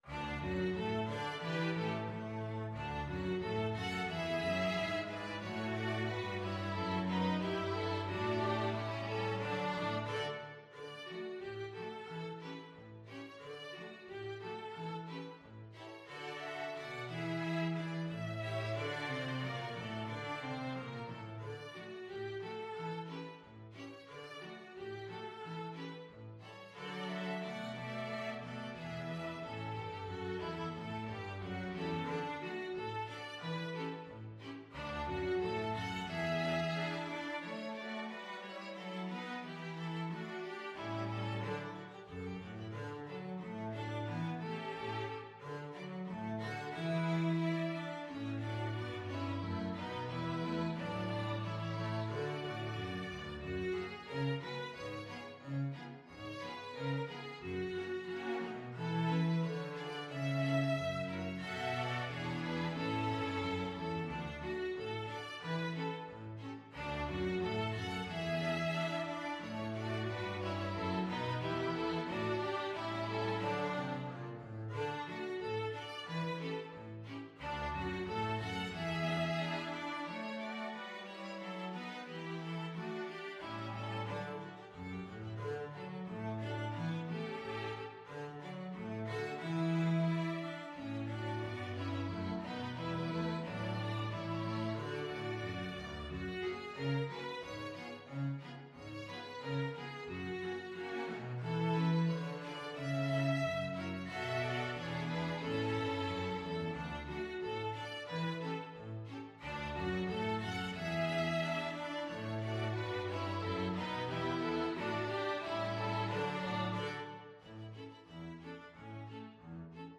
Violin 1Violin 2ViolaCelloDouble Bass
2/2 (View more 2/2 Music)
=90 Fast and cheerful
Pop (View more Pop String Ensemble Music)